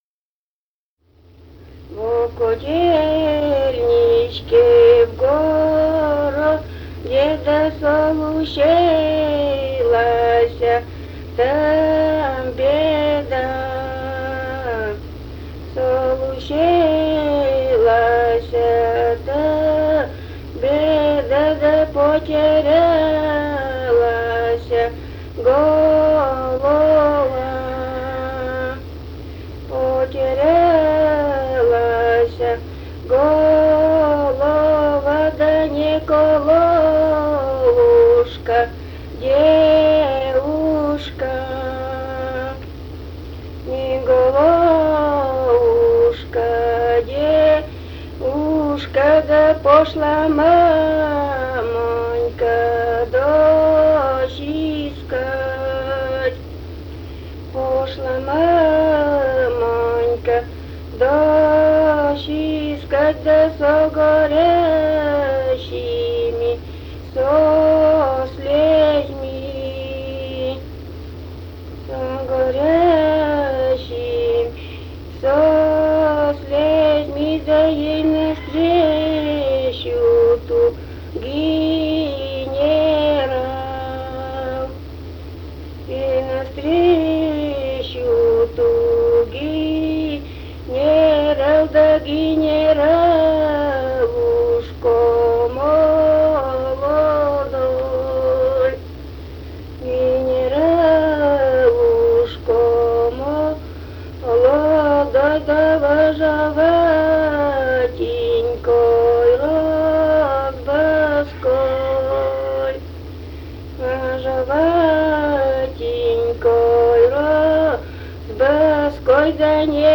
полевые материалы
«Во Котельничке в городе» (баллада).
Пермский край, г. Оса, 1968 г. И1074-29